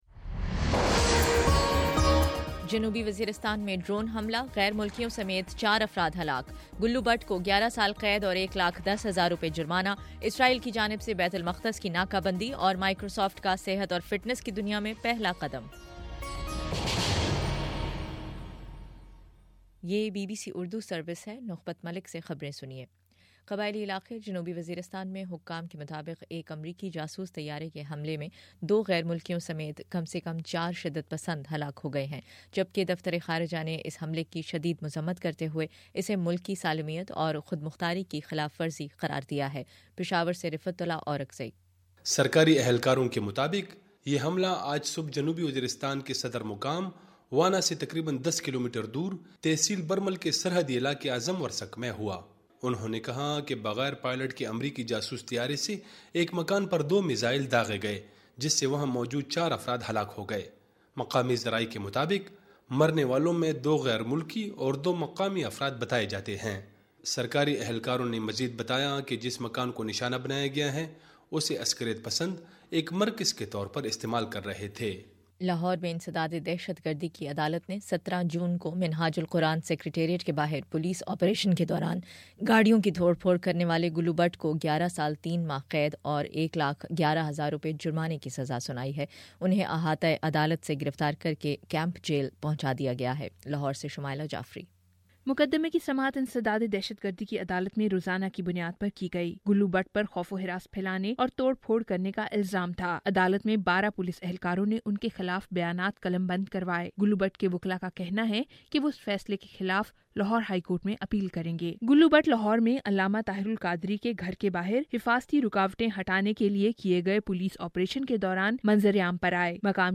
اکتوبر30: شام چھ بجے کا نیوز بُلیٹن